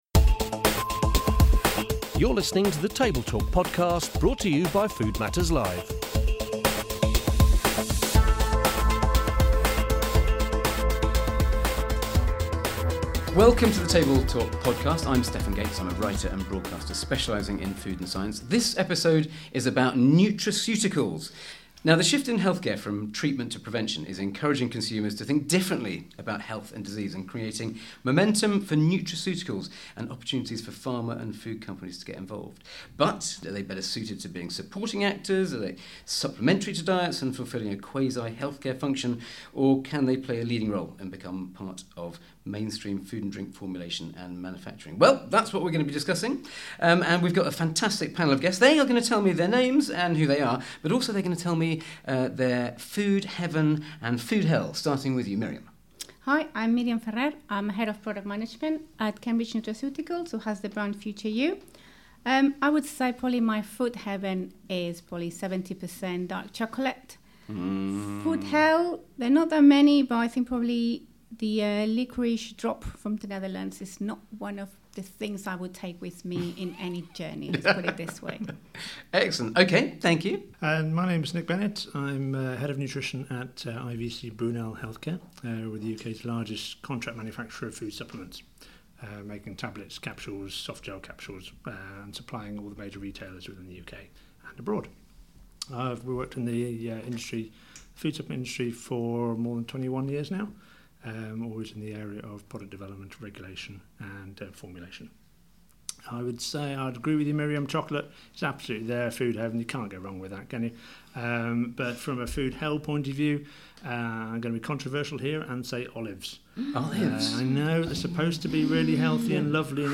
With the nutraceutical world growing host Stefan Gates is joined by an expert panel